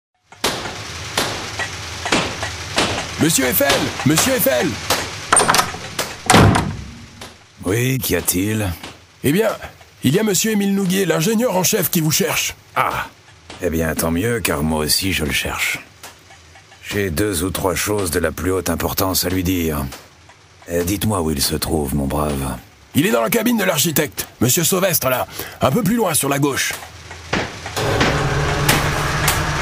Diffusion distribution ebook et livre audio - Catalogue livres numériques
Nous suivons le brillant ingénieur Gustave Eiffel dans les coulisses de ce chantier hors du commun. Six personnages sont présents : Gustave Eiffel, ses principaux collaborateurs Messieurs Sauvestre et Nouguier, sa fille, Claire, une narratrice et un ouvrier.